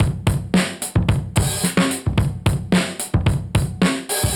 Index of /musicradar/dusty-funk-samples/Beats/110bpm/Alt Sound